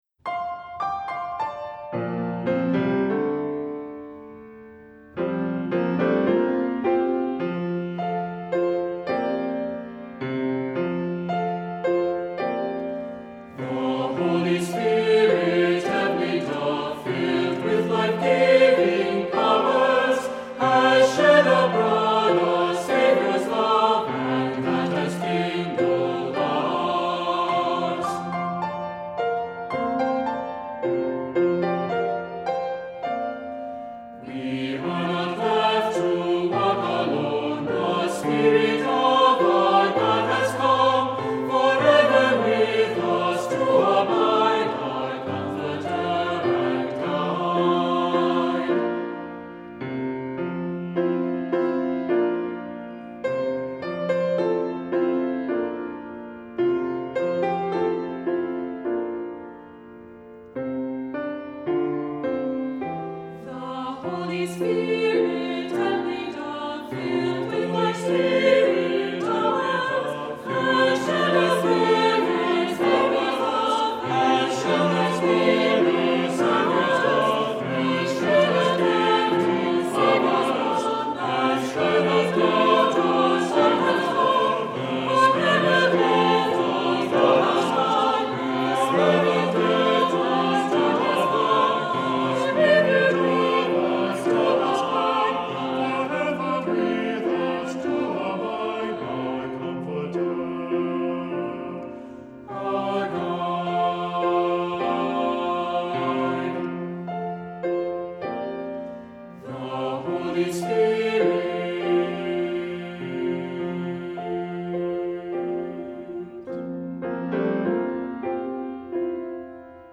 Voicing: Four-part equal; Four-part mixed